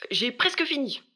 VO_ALL_Interjection_07.ogg